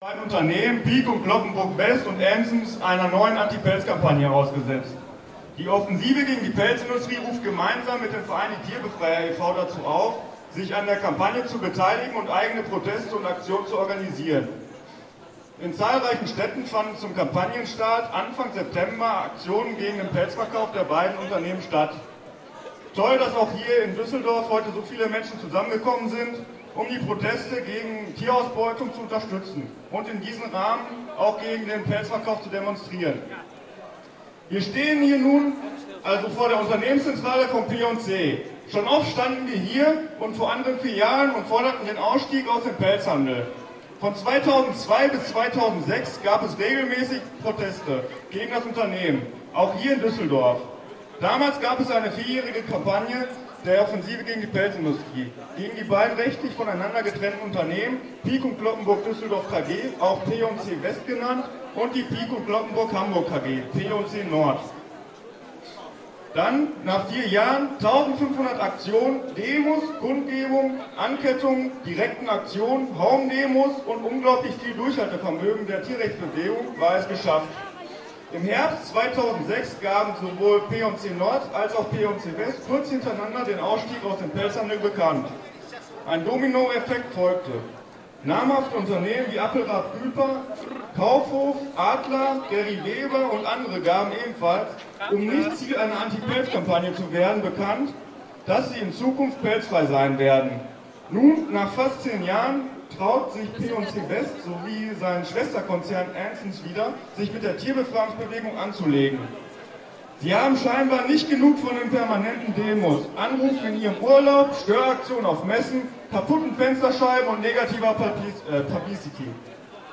Einige auf der Demonstration vor tierausbeutenden Restaurants und Läden gehaltene Ansprachen:
Die 3. Ansprache (Audio 3/4) [MP3]